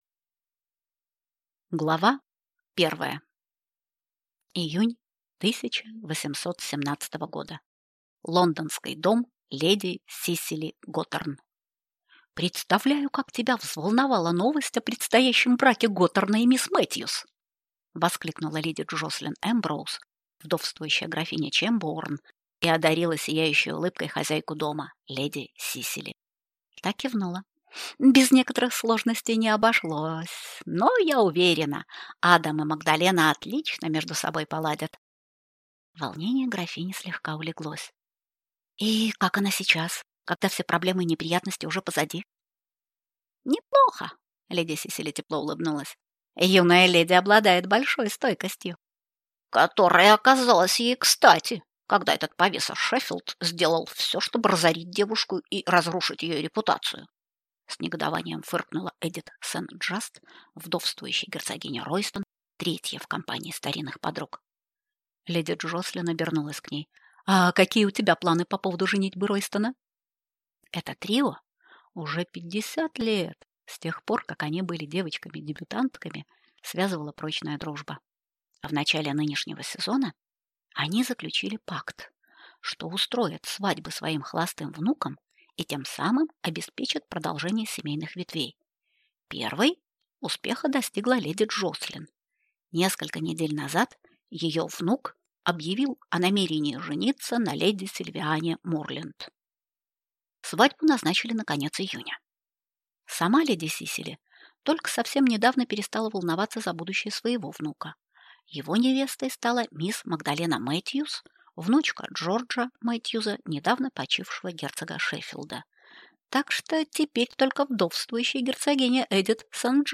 Аудиокнига Не просто скромница | Библиотека аудиокниг